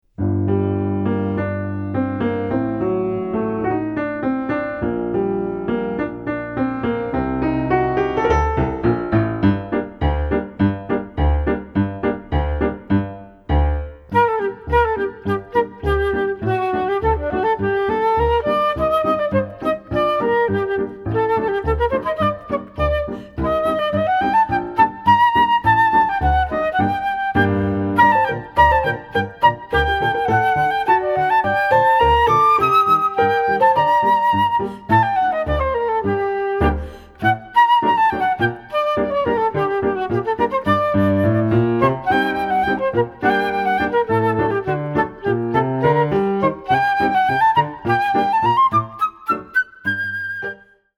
Pour flûte et piano